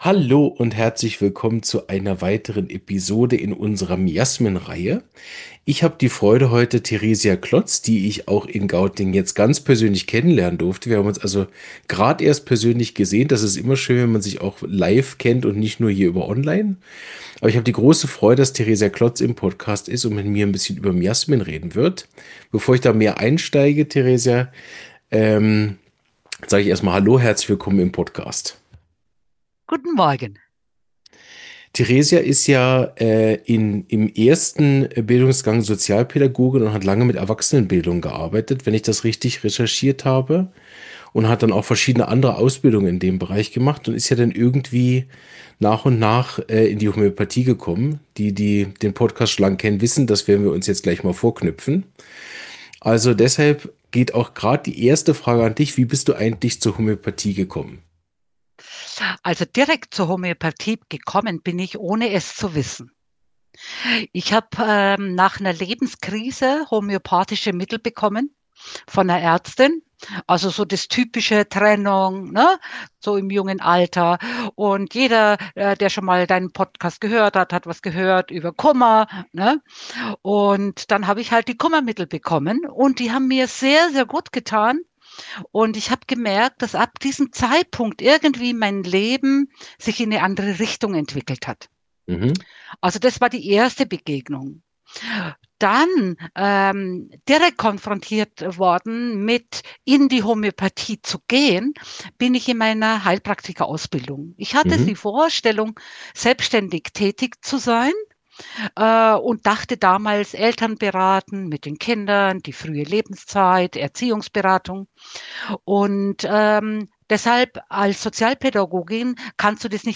Homöopathie - Podcast
Ein vielschichtiges Gespräch über die Verbindung von Empfindung, Miasmen und praktischer Begleitung in der homöopathischen Therapie.